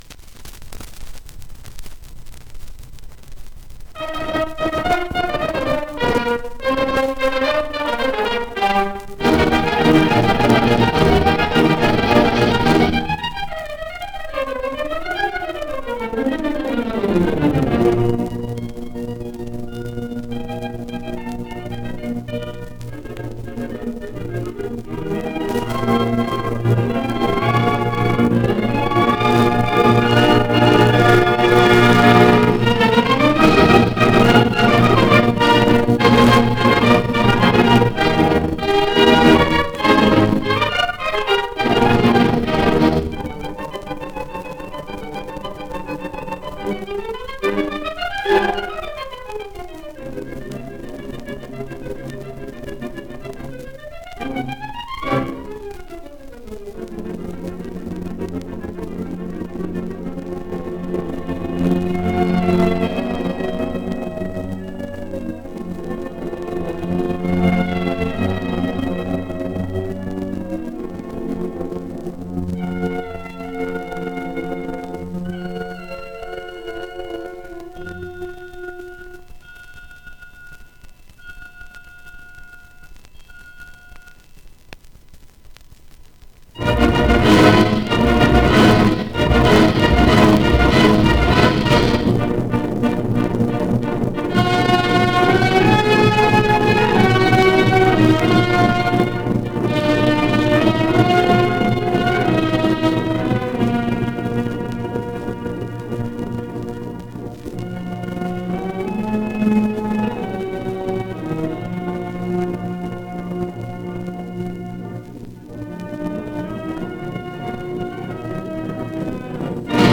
1 disco : 78 rpm ; 30 cm Intérprete
• Zarzuelas
• Música para banda